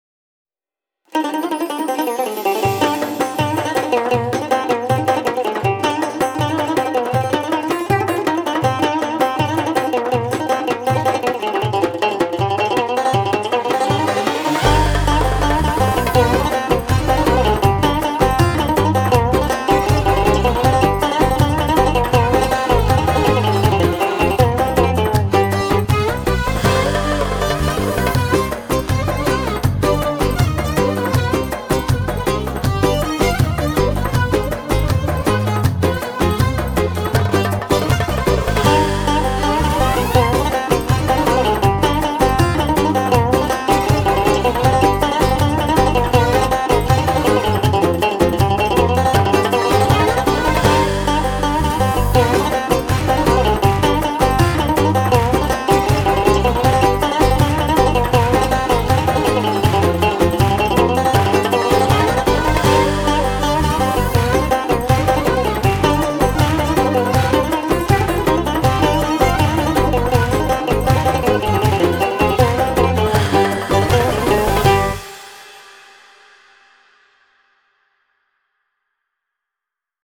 Ramadan Arabian Middle East Music with oriental vibe.